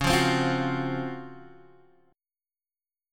C#M11 Chord
Listen to C#M11 strummed